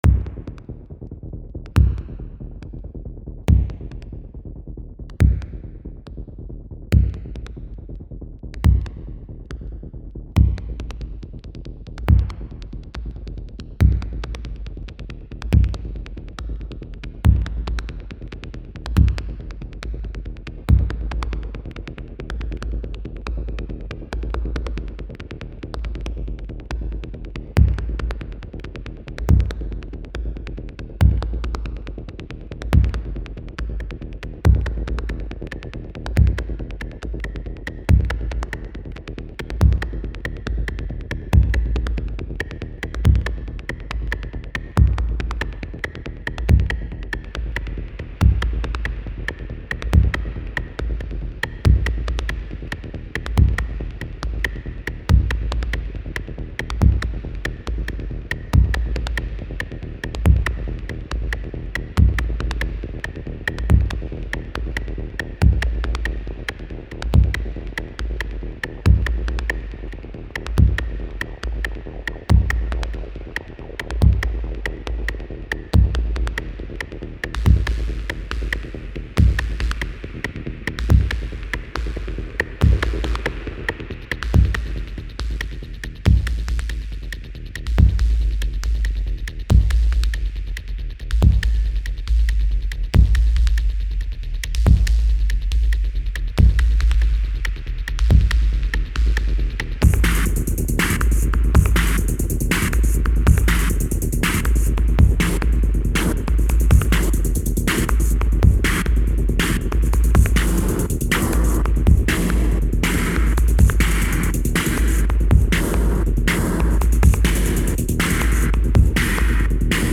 Roland TR 808 Rhythm Composer Roland TB 303 Bass Line